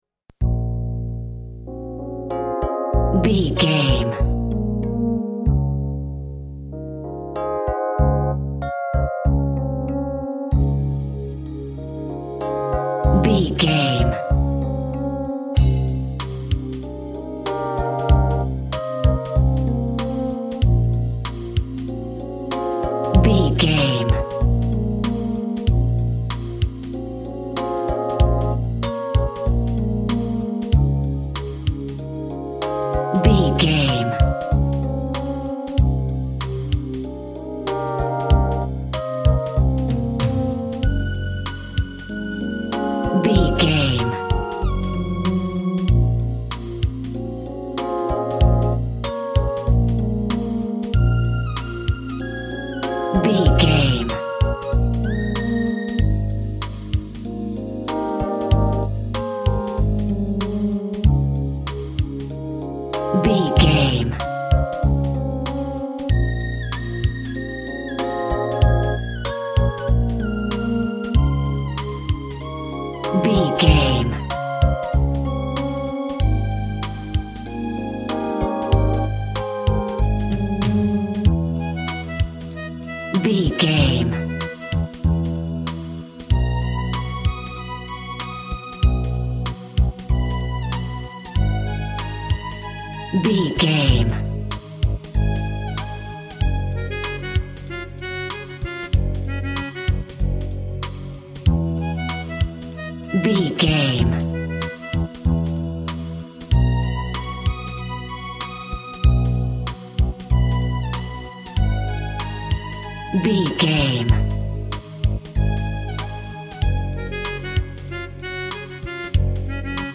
Power Pop in the 80s.
Aeolian/Minor
energetic
uplifting
groovy
drum machine
electric guitar
synthesiser
pop rock
synth lead
synth bass